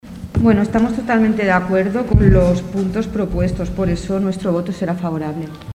El Ple de l’Ajuntament de Tordera va aprovar una moció per controlar la digitalització dels serveis bancaris.
Per part de Ciutadans, Miriam Rocabruna presenta el vot favorable: